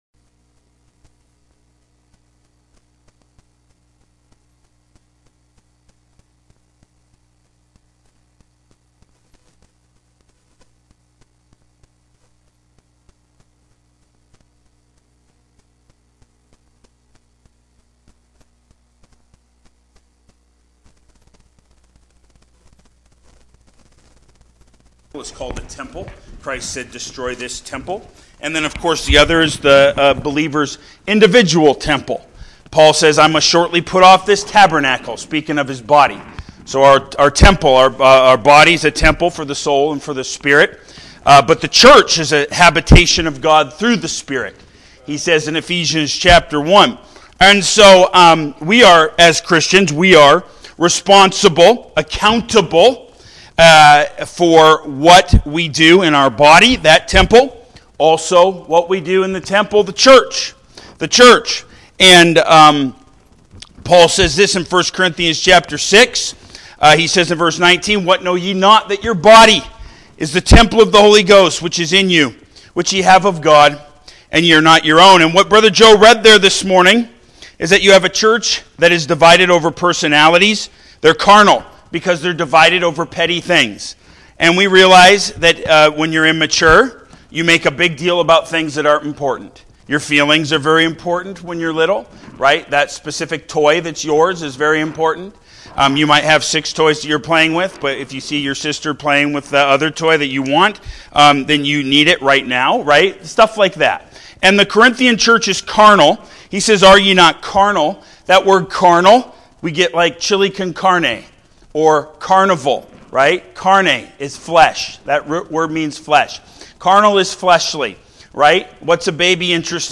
Main Service